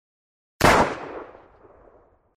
Звуки пистолета
2. Произвели выстрел из пистолета Макарова